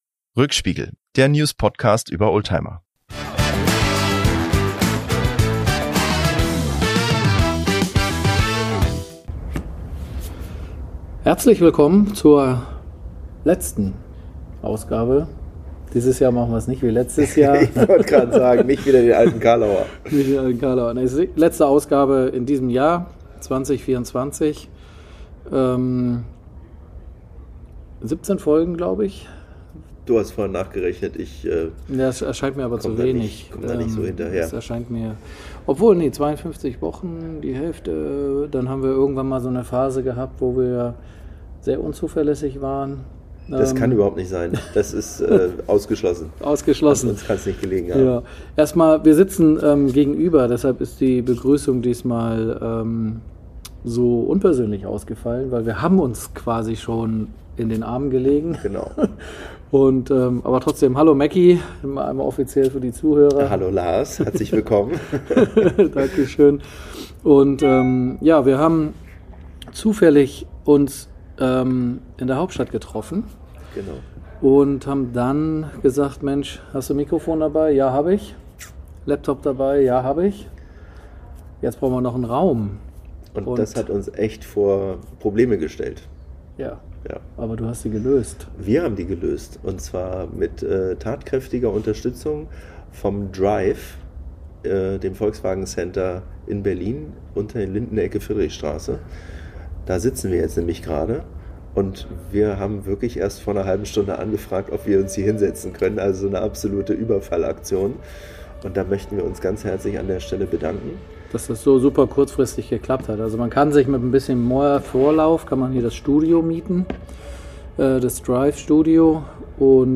Die Aufnahme fand im VW Drive in Berlin-Mitte statt, wo sie über die jüngste Vergangenheit und die unmittelbar bevorstehende Zukunft sprechen.